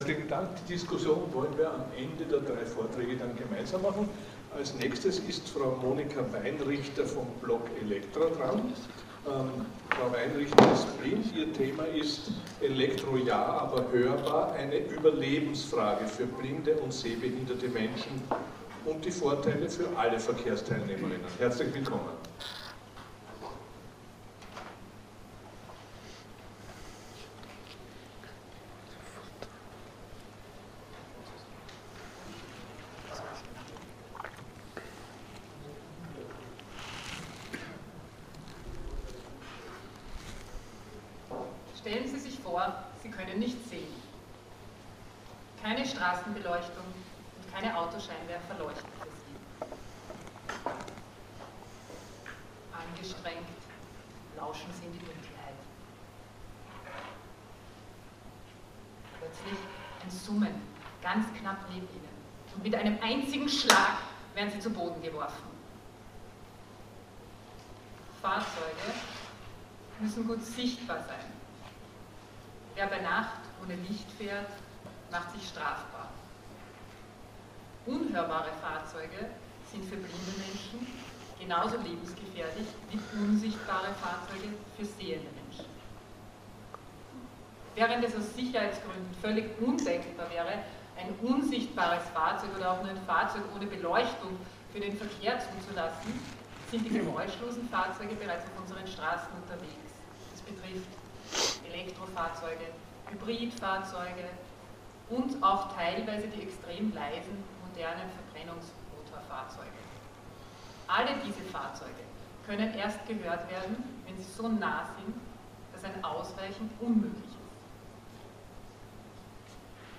Vortrag beim Forschungsforum des BMVIT